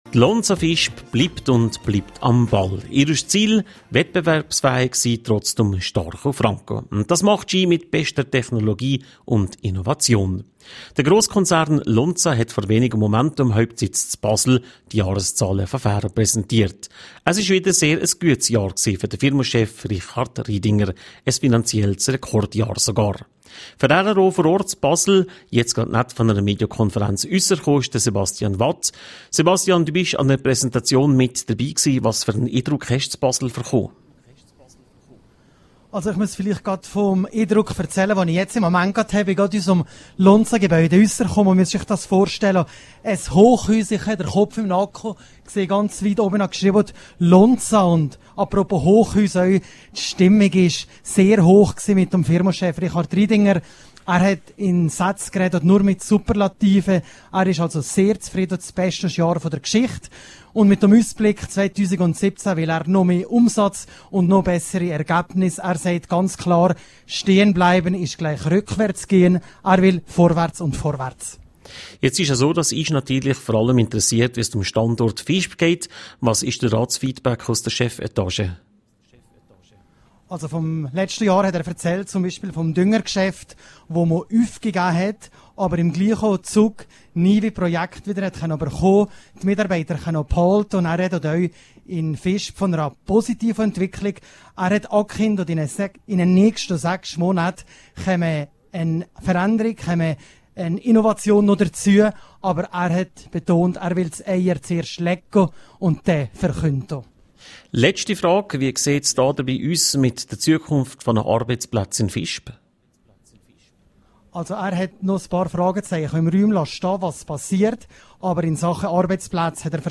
Live-Schalte von der Medienorientierung zum Lonza-Ergebnis